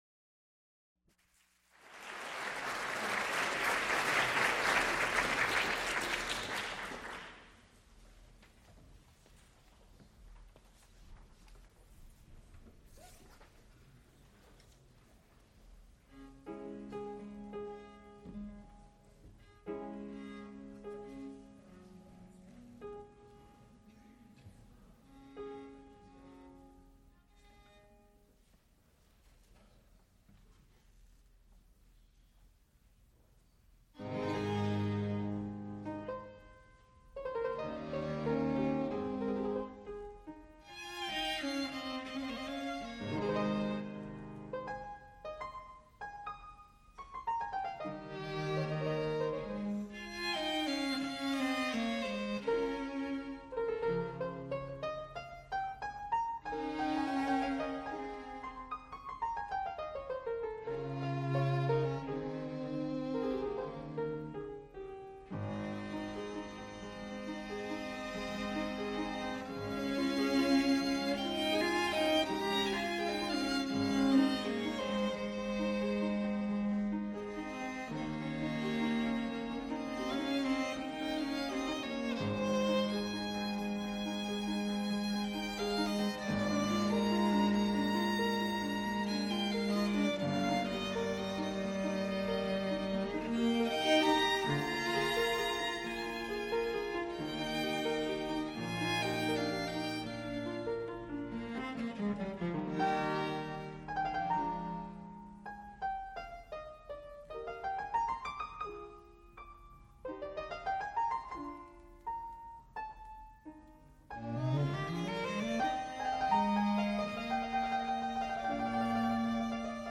fortepiano
violin
cello), on authentic instruments., Recorded live October 16, 1979, Frick Fine Arts Auditorium, University of Pittsburgh.
Extent 2 audiotape reels : analog, quarter track, 7 1/2 ips ; 12 in.
musical performances
Piano trios